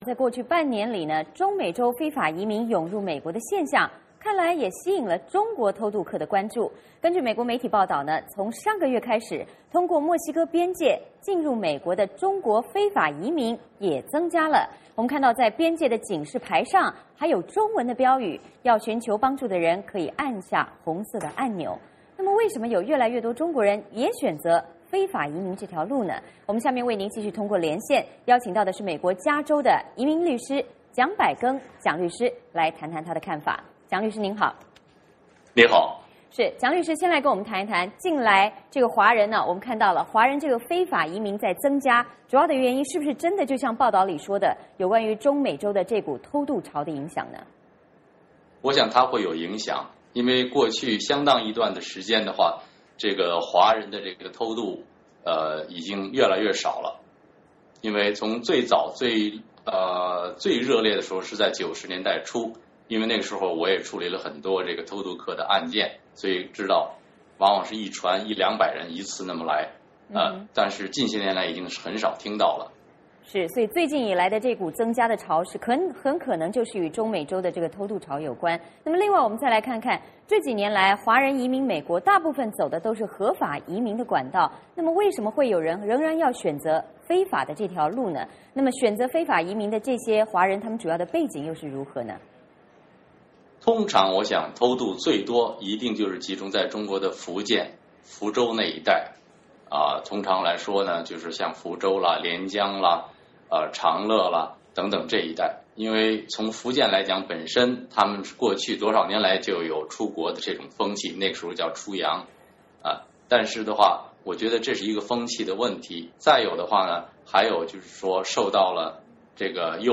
为何越来越多中国人也选择非法移民这条路?下面我们通过连线